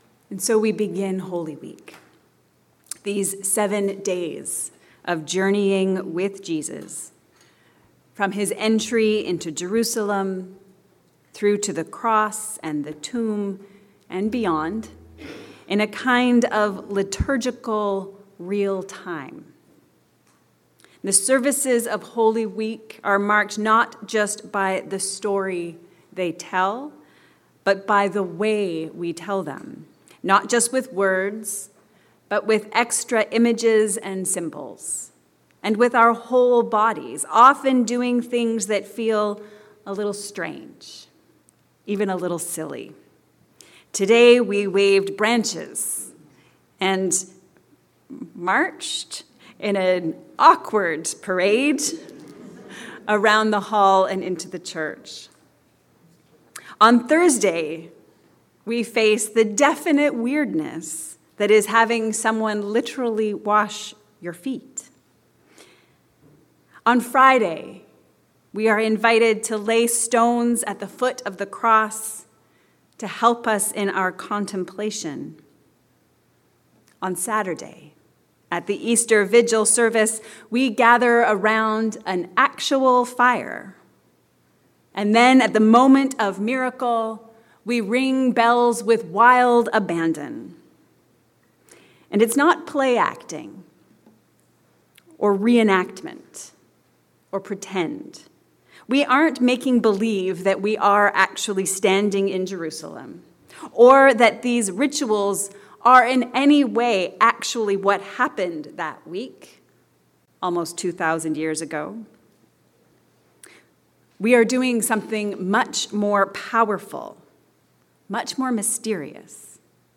A Story for Living. A sermon for Passion Sunday